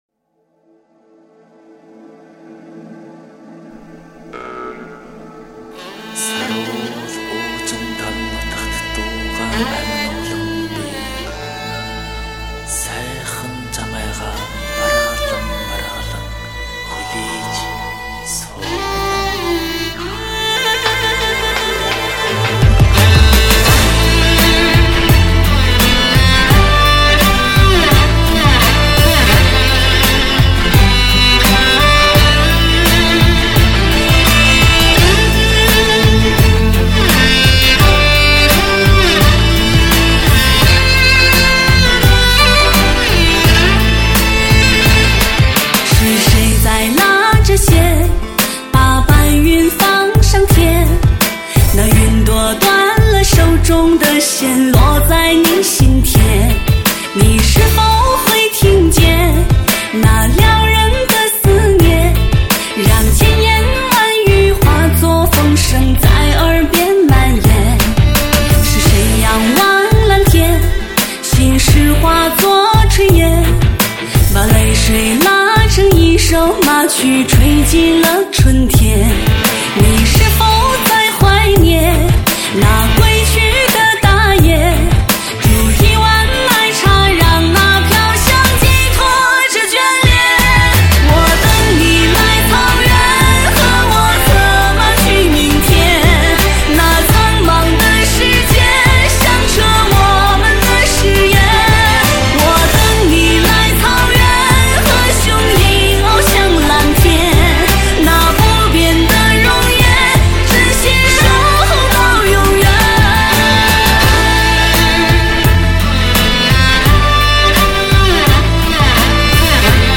专辑格式：DTS-CD-5.1声道
多元化曲风 五彩缤纷民族乐韵 再创民族音乐新高峰